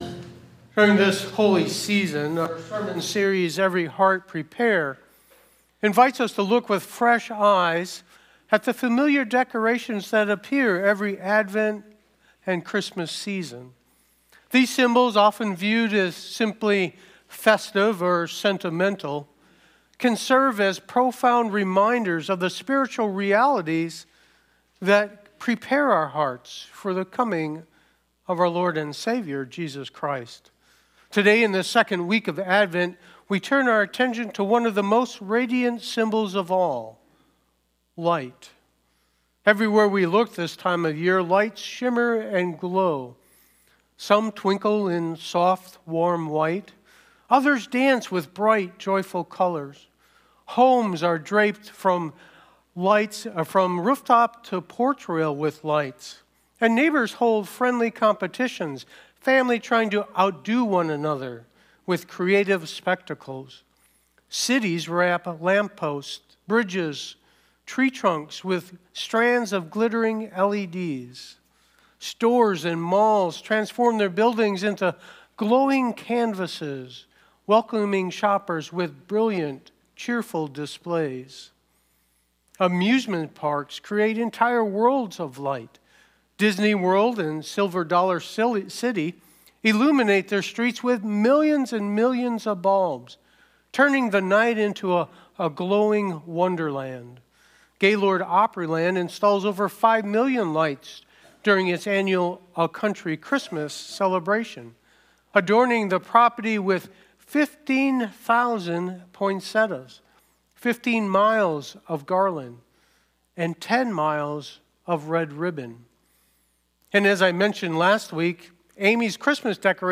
Sermons – Page 6